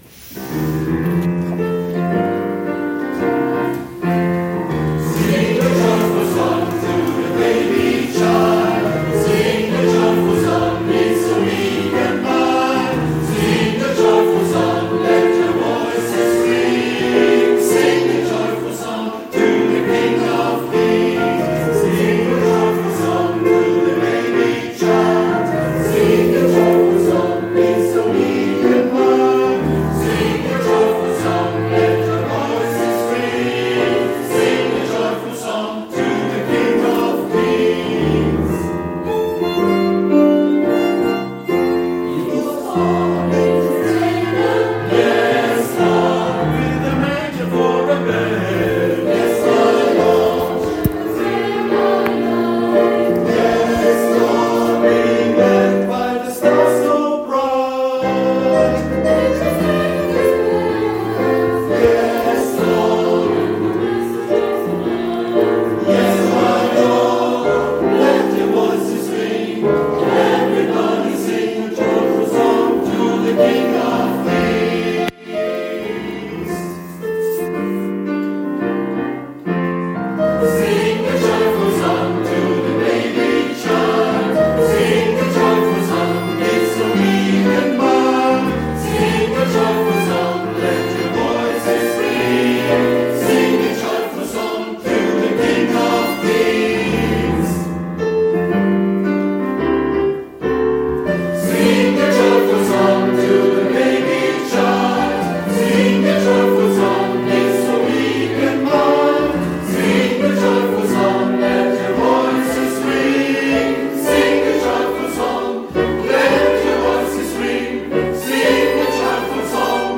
Musikalische Mitgestaltung durch unsere neue Singgemeinschaft